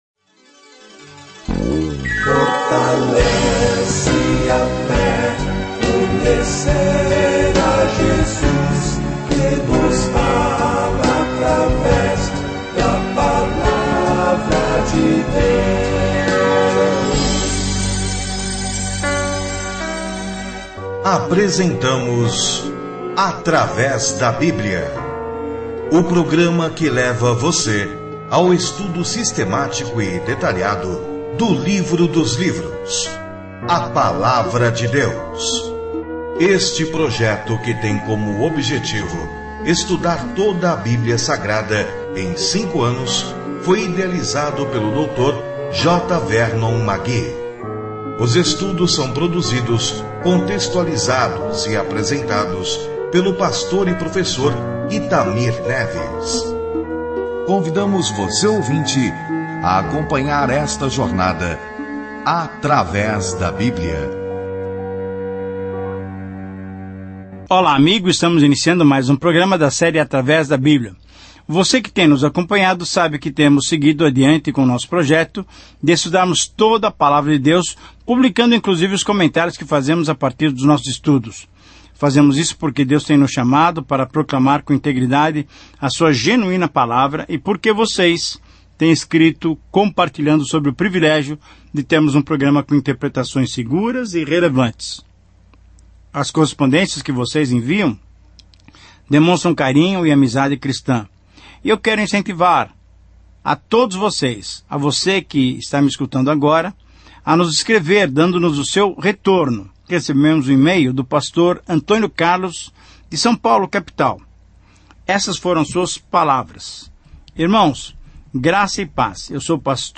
As Escrituras Ezequiel 20:1-49 Dia 14 Começar esse Plano Dia 16 Sobre este Plano O povo não quis ouvir as palavras de advertência de Ezequiel para retornar a Deus, então, em vez disso, ele encenou as parábolas apocalípticas, e isso perfurou o coração das pessoas. Viaje diariamente por Ezequiel enquanto ouve o estudo em áudio e lê versículos selecionados da palavra de Deus.